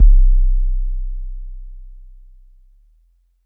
SOUTHSIDE_808_lorider_C.wav